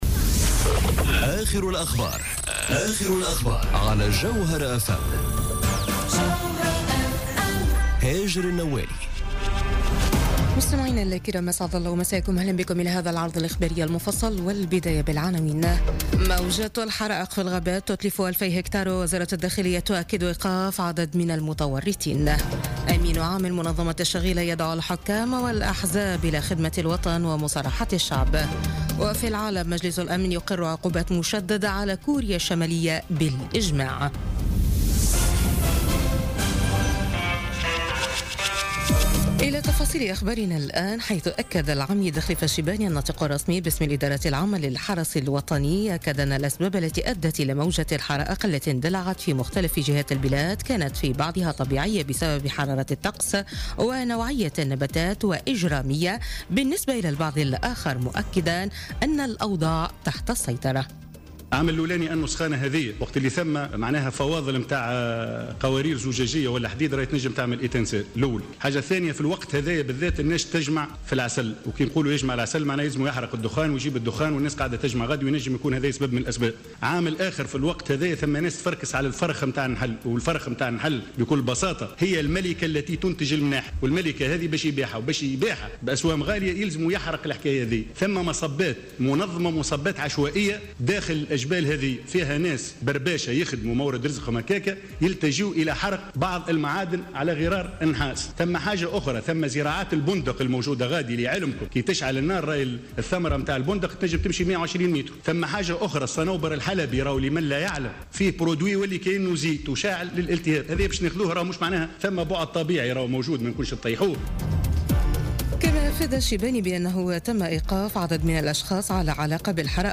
نشرة أخبار منتصف الليل ليوم الأحد 6 أوت 2017